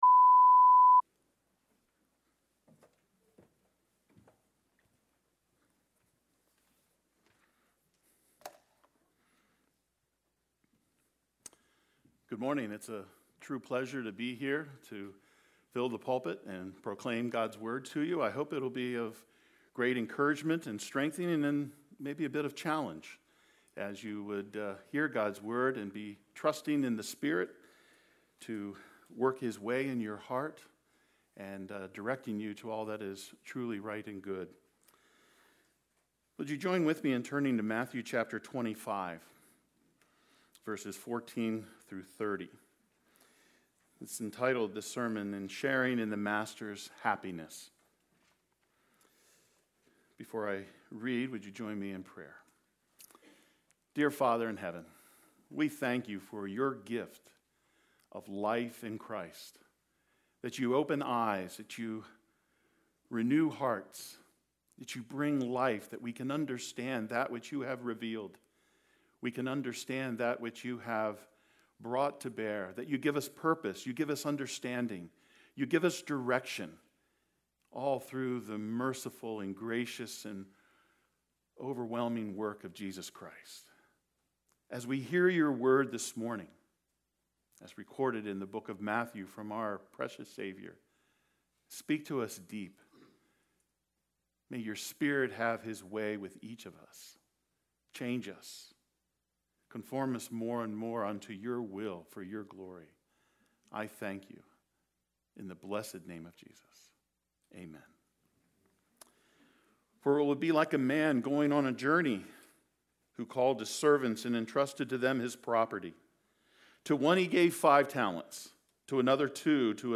6.12.22-sermon-audio.mp3